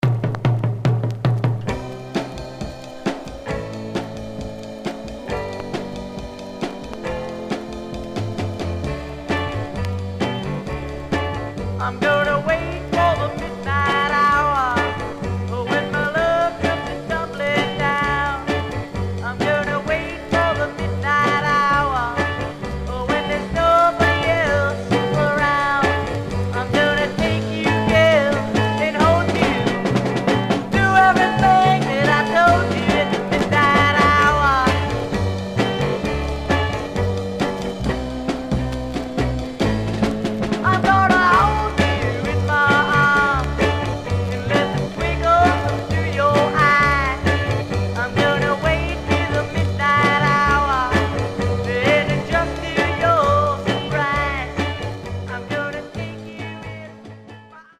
Stereo/mono Mono
Garage, 60's Punk ..........👈🏼 Condition